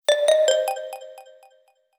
Dark_Alley_alert.ogg